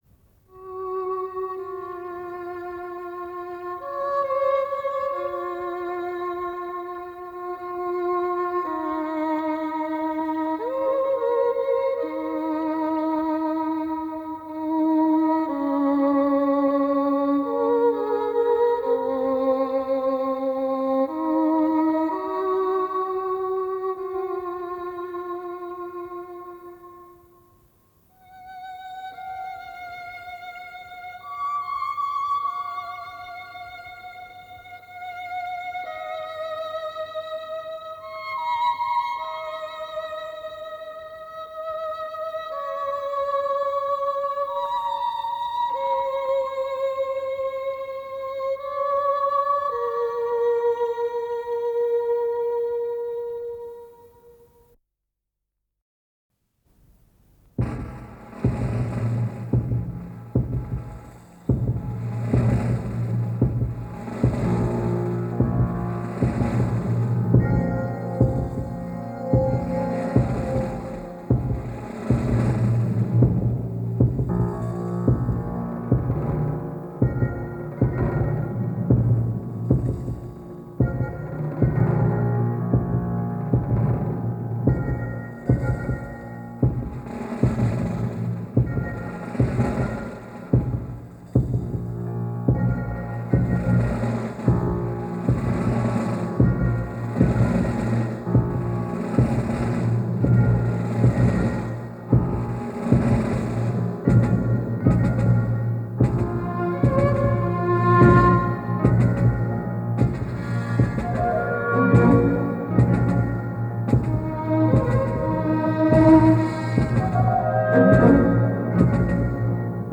с профессиональной магнитной ленты
Solo скрипки без сопровожд. (лирич.)
Andante (фант. драматич.)
-нечитаемо- (аккомпанемент) мягкий лирич.
Andante (рассвет)
Andante (лирич.)
Allegretto (аккомпанемент)